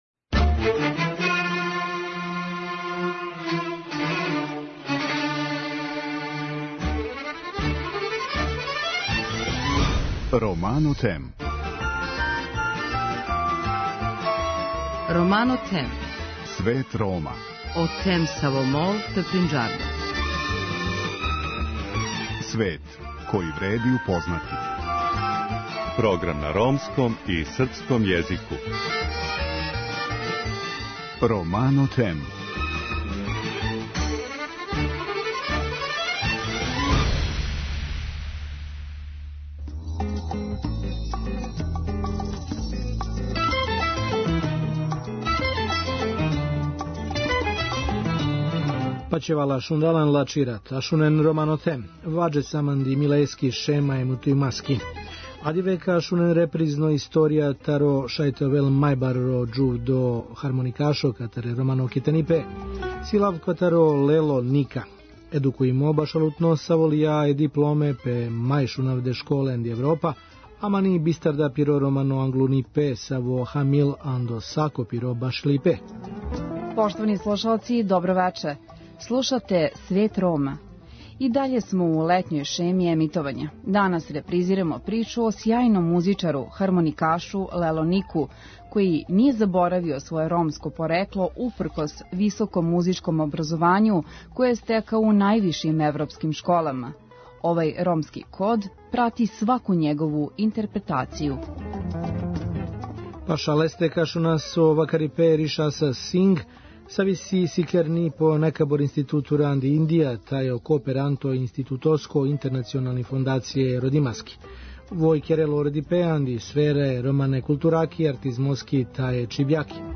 У другом делу емисије чућете разговор